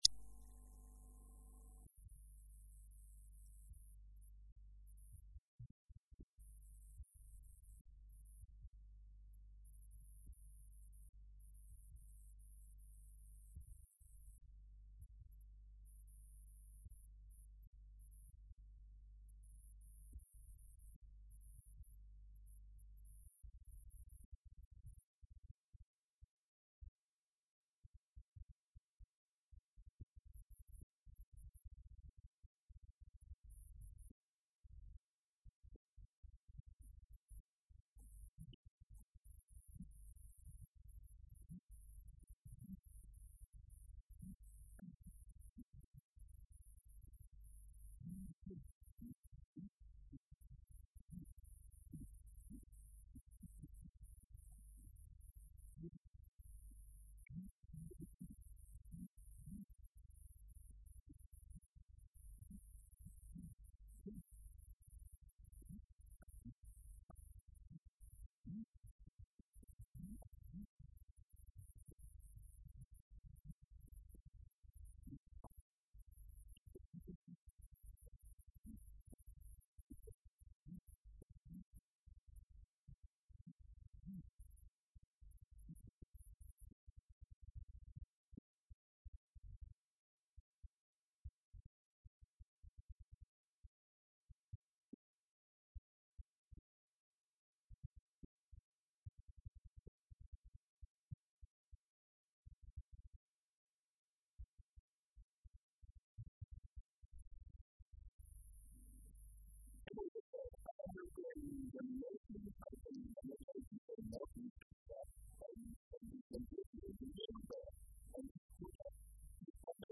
special guest on this edition of News Extra on Foundation radio FM 100, Bamenda Cameroon